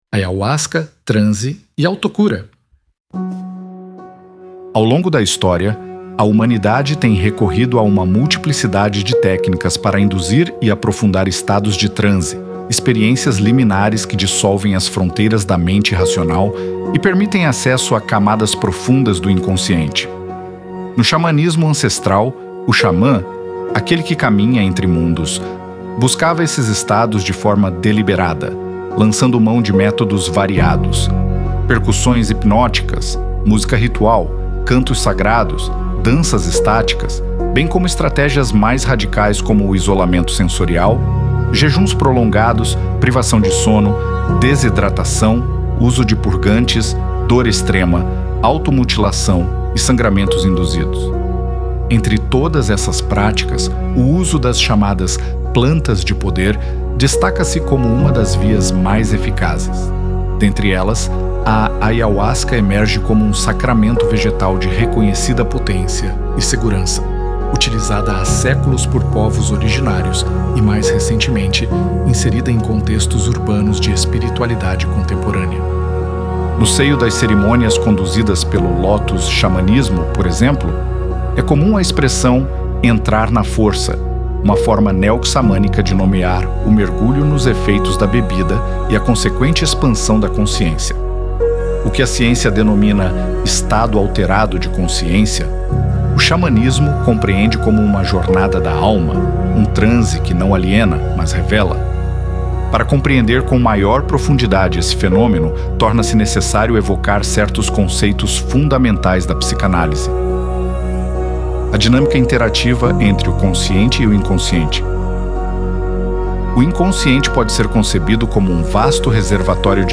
Dificuldade para ler? Ouça a leitura do artigo aqui